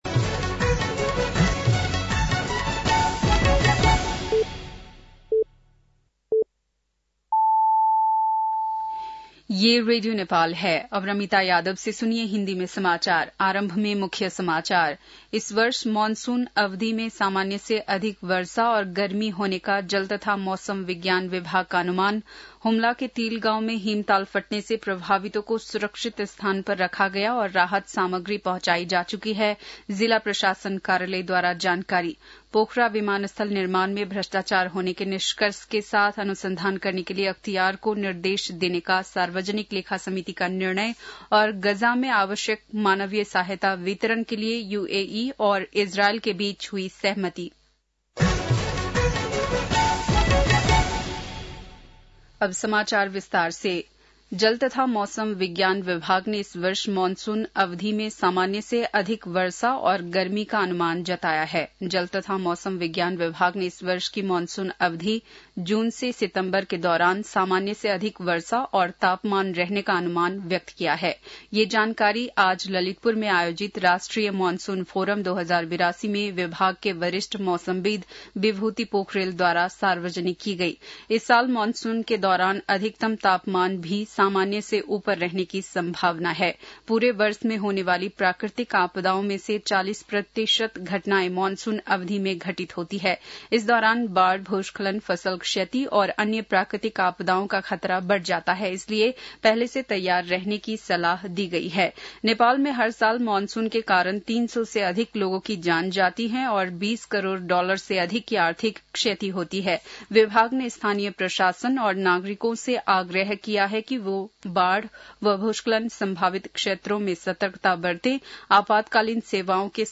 बेलुकी १० बजेको हिन्दी समाचार : ७ जेठ , २०८२